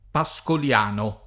[ pa S kol L# no ]